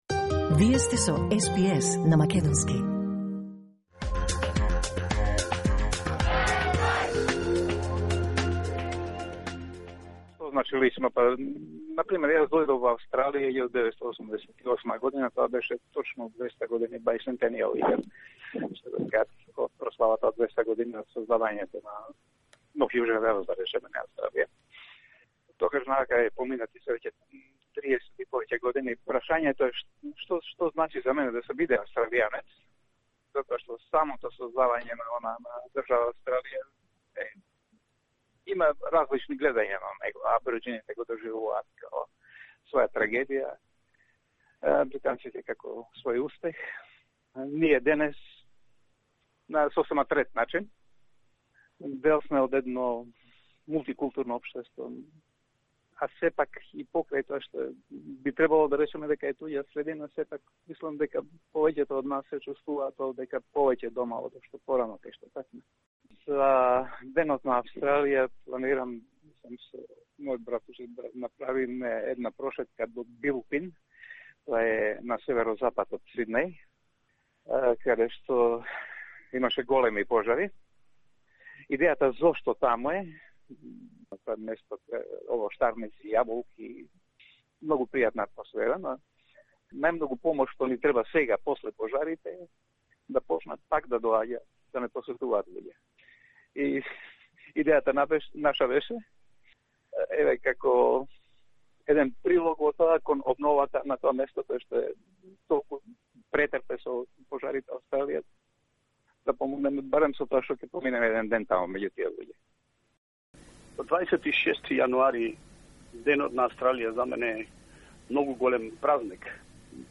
aust_day_vox_pop_za_veb.mp3